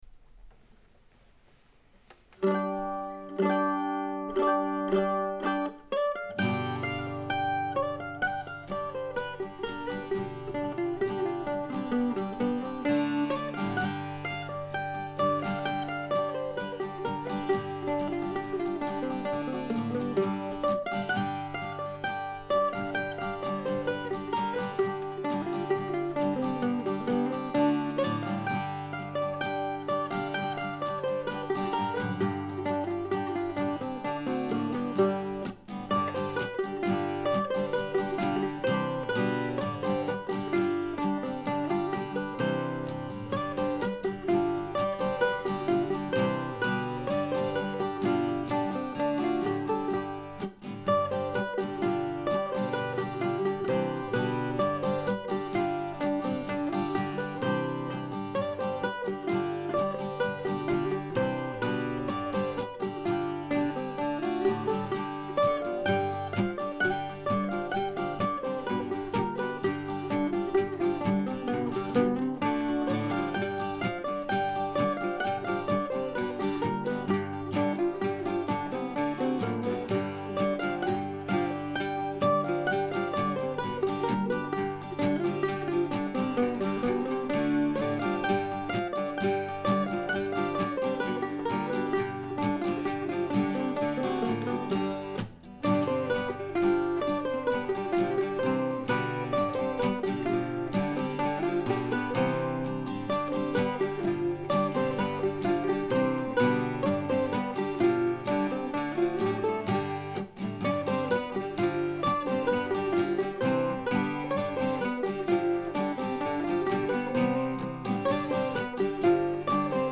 Just the melody three times through; first on mandolin, then with octave mandolin added, finally with guitar playing the tune also on the third time. I took it at a relaxed tempo but it can go really fast if you prefer that approach.